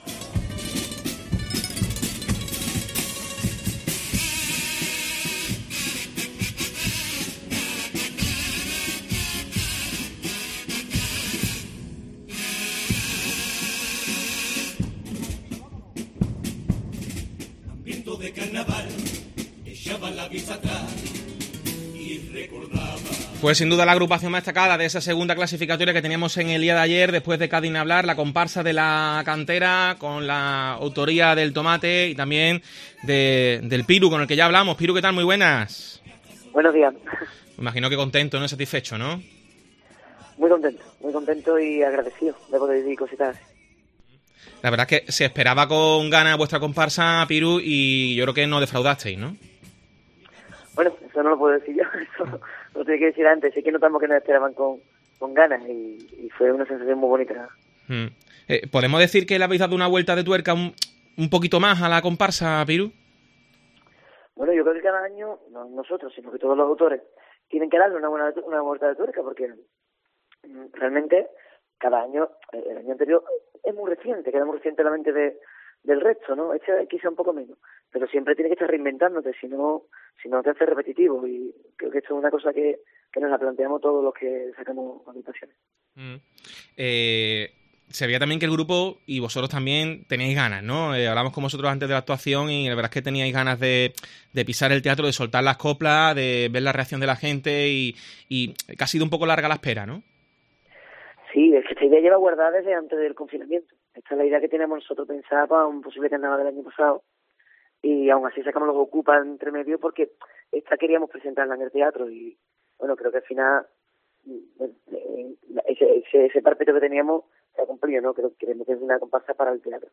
"Claro que sí, nos encantaría estar en la final y soñamos con ello, pero eso no quiere decir que lo merezcamos, todo ello lo tendrá que decir el jurado", explica uno de los autores de la comparsa.